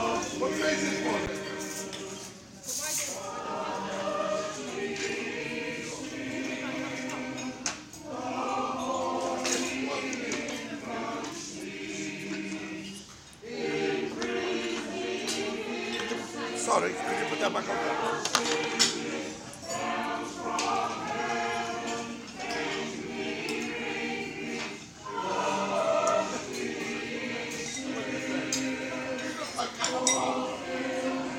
Essington fruit farm singers